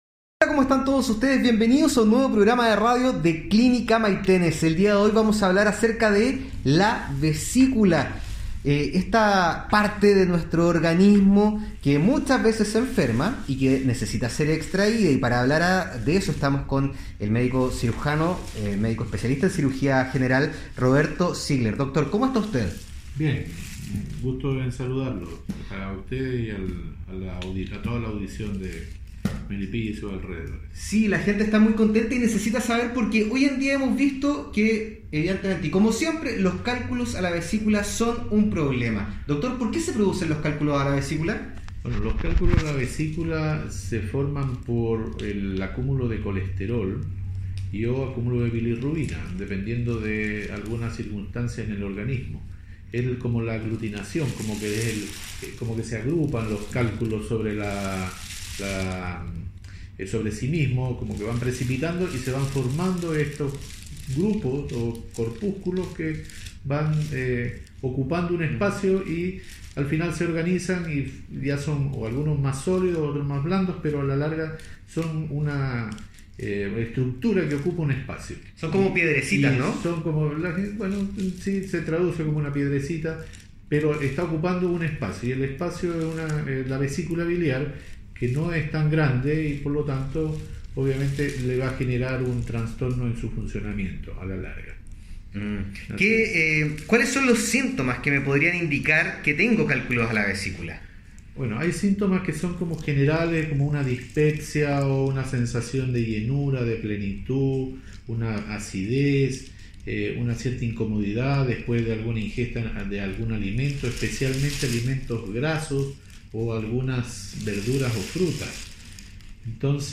Programa radial Clínica Maitenes